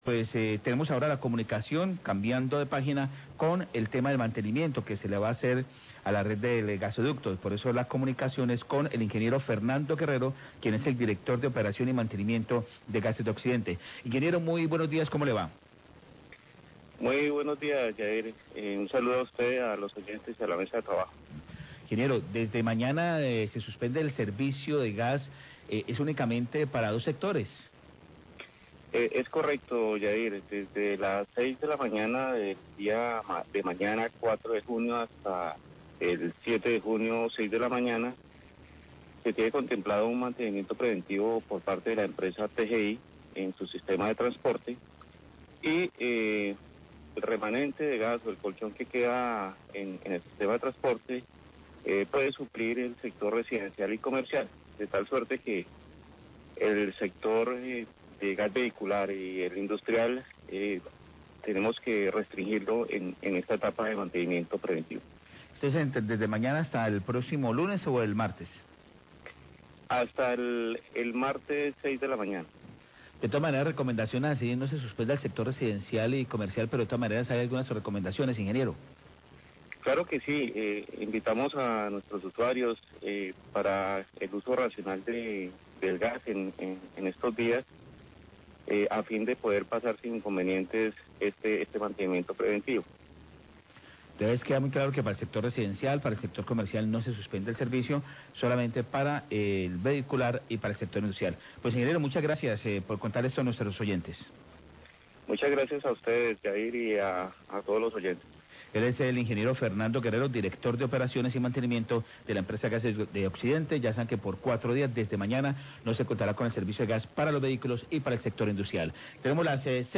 Radio
entrevista